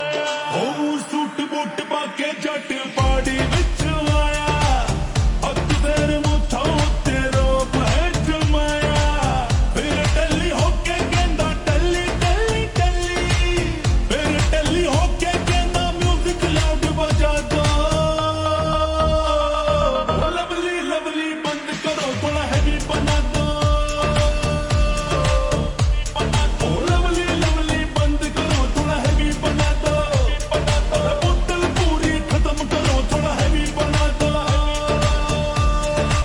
Punjabi Songs
Slow Reverb Version
• Simple and Lofi sound